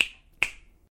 snap1.wav